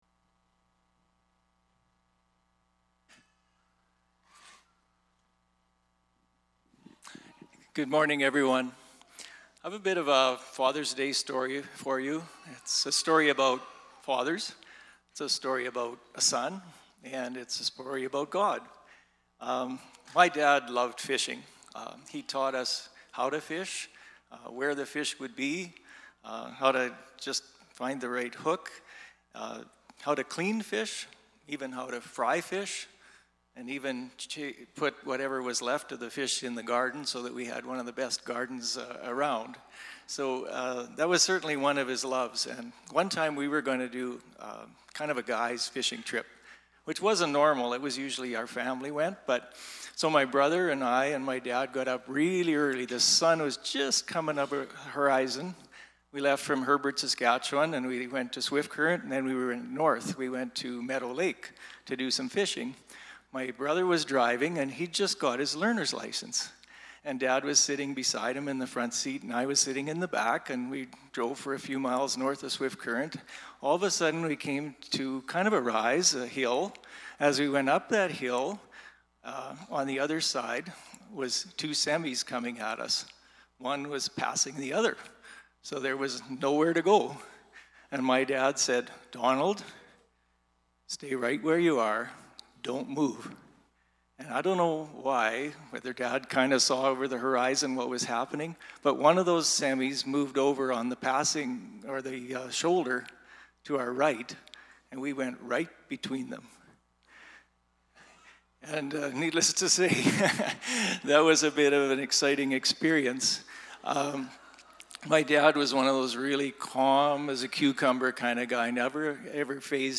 Sermons | Brooks Evangelical Free Church